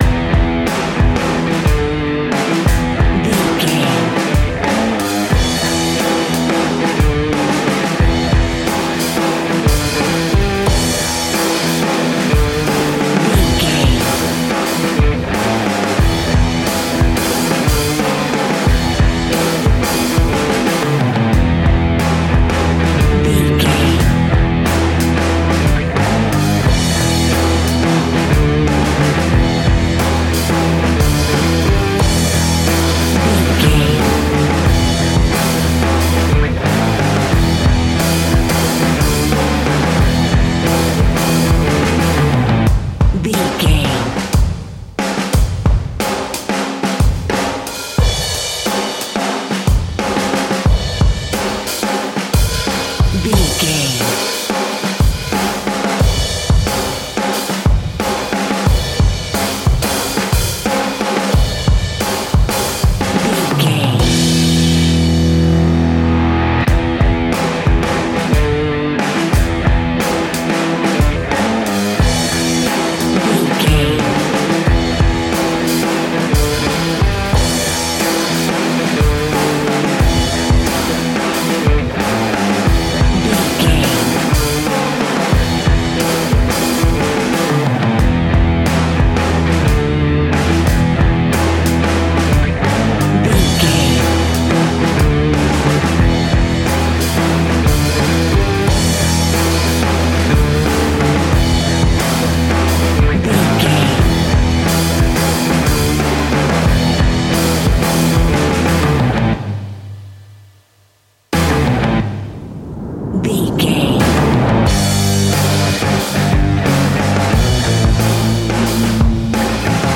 Ionian/Major
hard rock
heavy rock
distortion